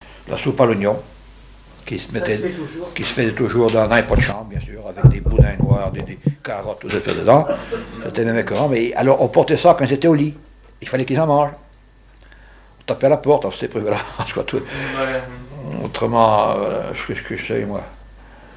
Répertoire et souvenir des musiciens locaux
Catégorie Témoignage